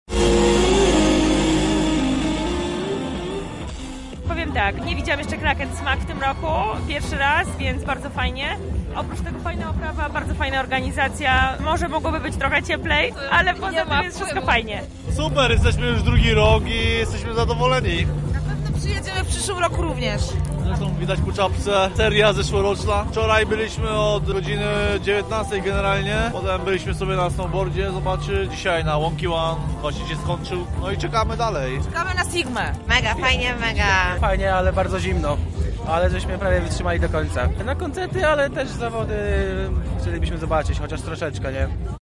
W piątek i sobotę reporterzy Radia Centrum wzięli udział w wydarzeniach w ramach SnowFestu.
Wrażeniami podzielili się uczestnicy: